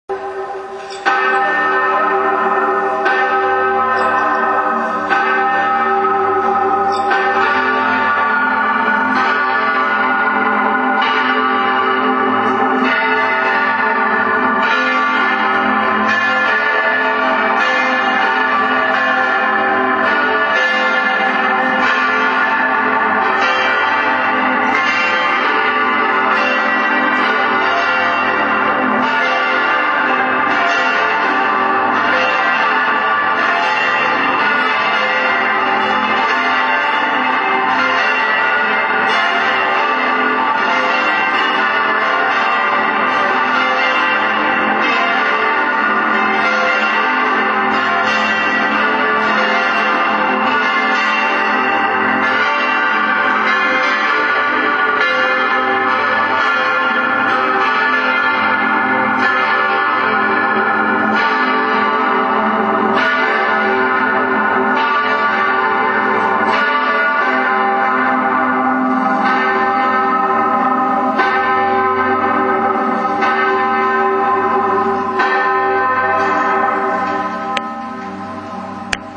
HlKreuz alle kurz - Die Glocken der Pfarrkirche Marling